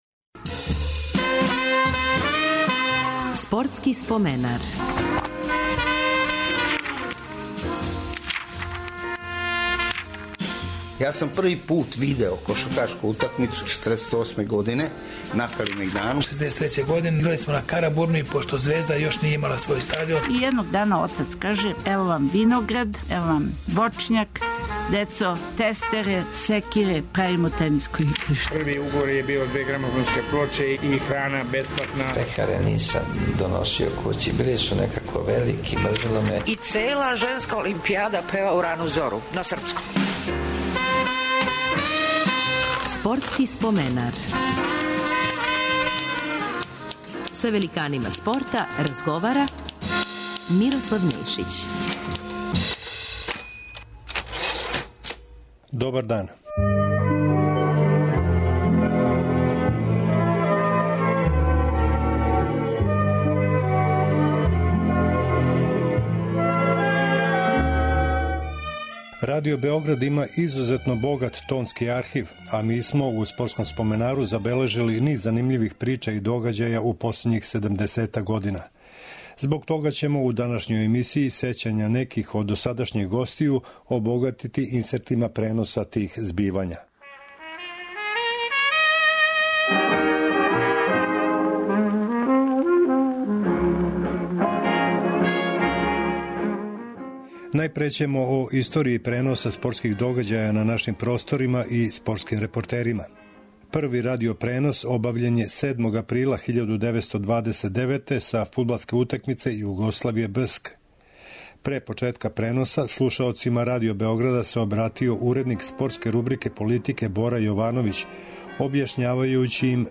У 163. Спортском споменару сећања неких од досадашњих гостију илустроваћемо одговарајућим инсертима преноса из богатог садржаја Тонског архива Радио Београда.